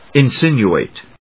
in・sin・u・ate /ɪnsínjuèɪt/